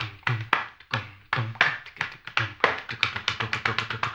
HAMBONE 17-R.wav